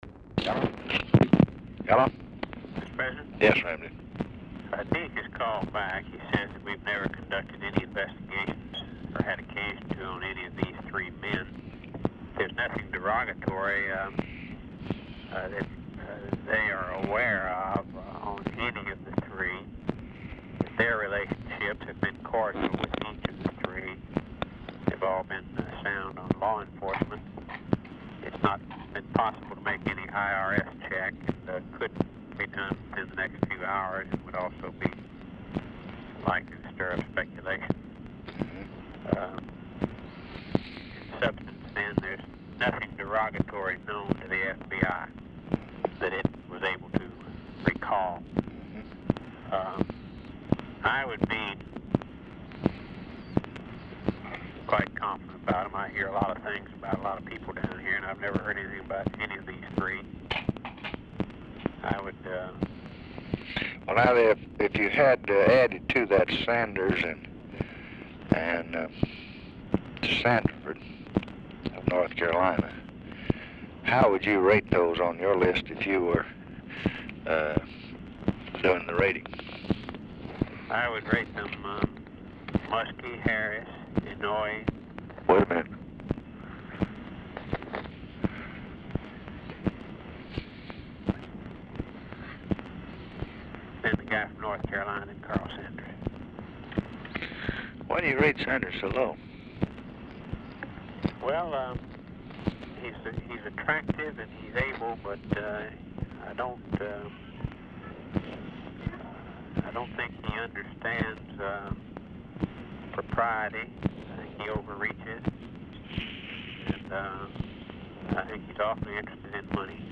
SMALL CHILD IS AUDIBLE IN BACKGROUND
Format Dictation belt
Location Of Speaker 1 LBJ Ranch, near Stonewall, Texas
Specific Item Type Telephone conversation